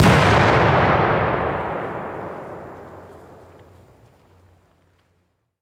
Explosion_001
blast Detonation Explosion sound effect free sound royalty free Memes